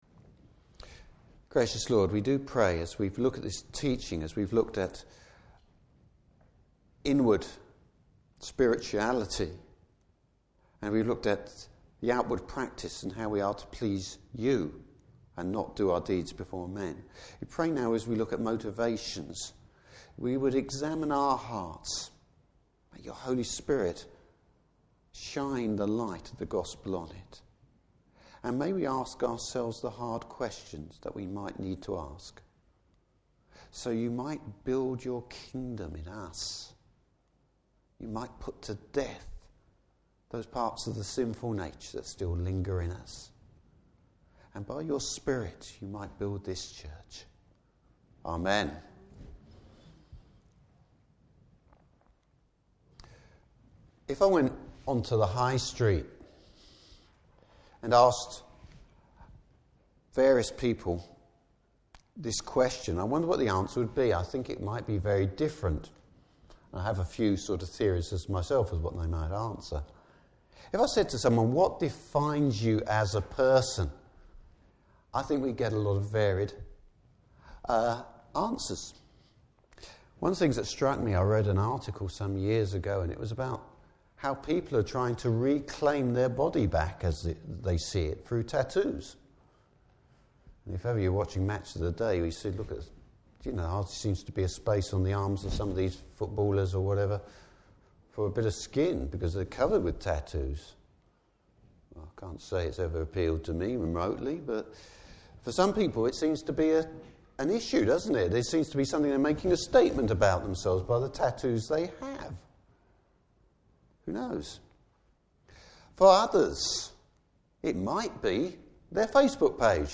Service Type: Morning Service Bible Text: Matthew 6:19-34.